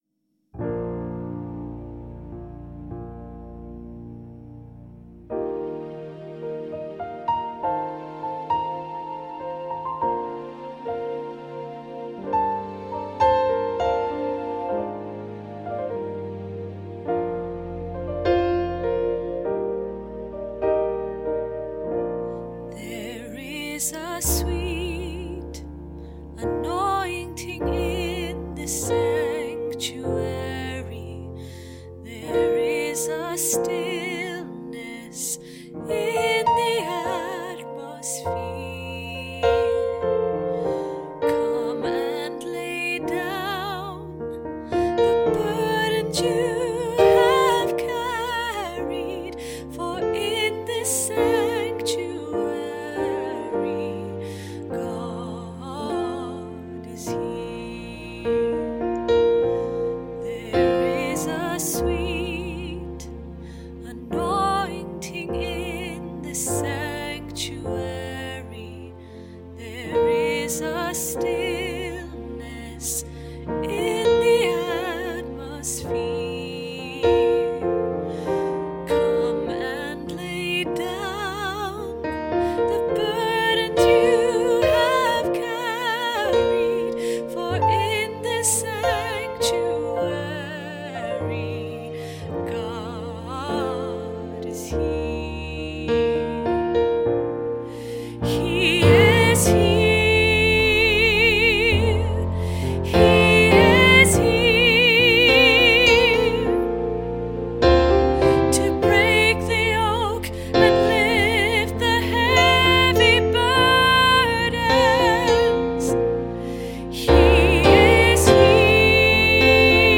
God is Here Alto Bass MP3 - Three Valleys Gospel Choir